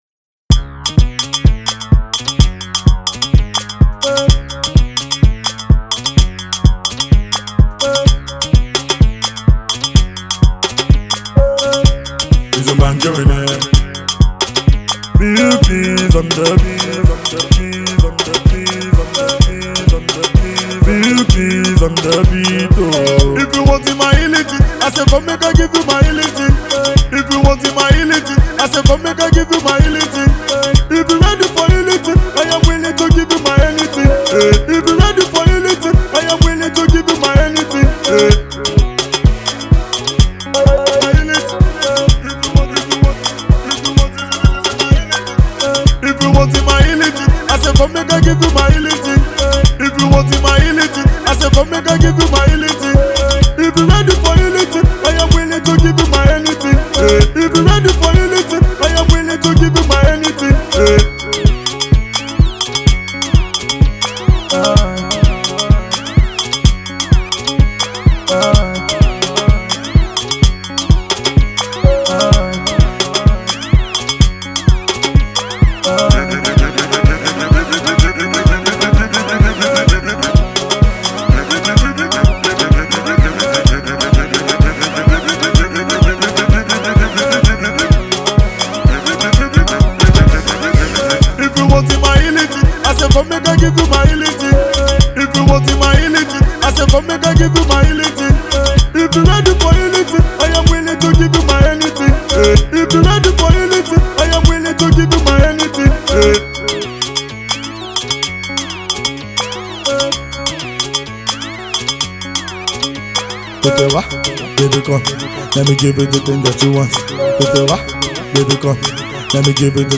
is a party starter